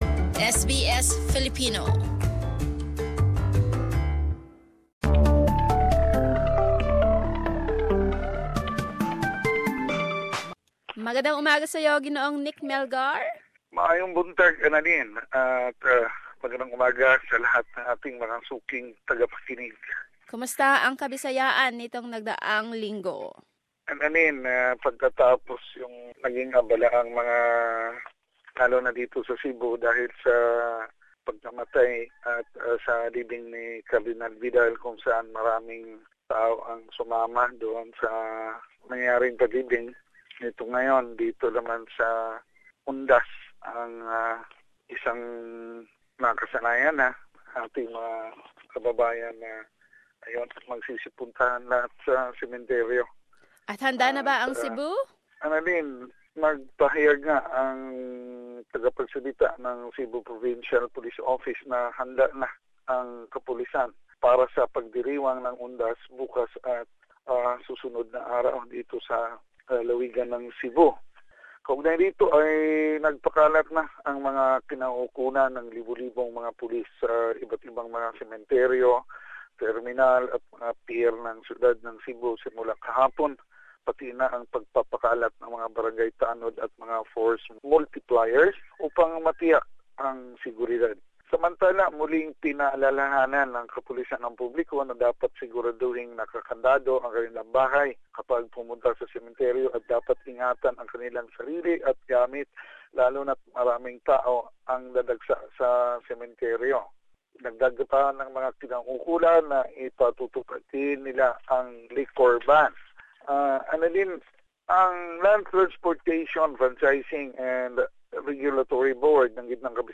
News from the Visayas: Province of Cebu still the richest province among 81 provinces in the Philippines, according to the 2016 Commission on Audit report. Preparations for security and safety for the commemorization of the All Soul's Day; and other news.